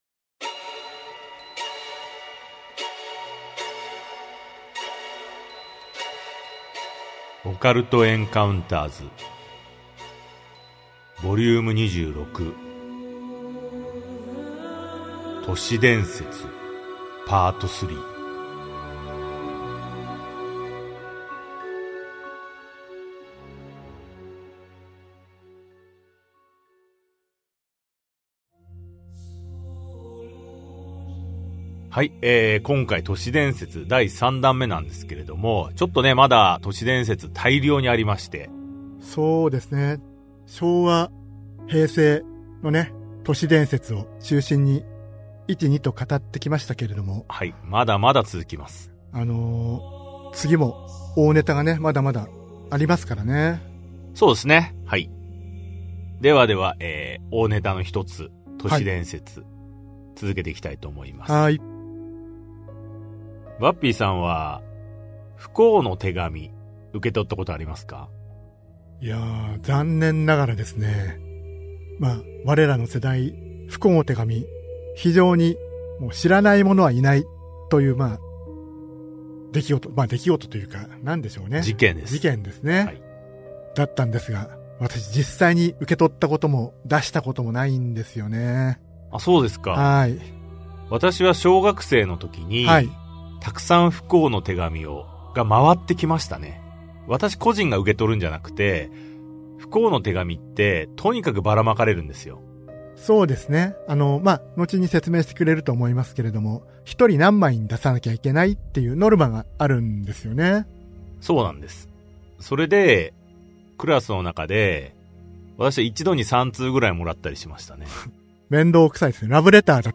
[オーディオブック] オカルト・エンカウンターズ オカルトを推理する Vol.26 都市伝説 3
千年以上の歴史を持つ特級 “都市伝説” を巡る数々の闇を、オカルト・エンカウンターズの2人が資料を元に考察し、鋭く推理する。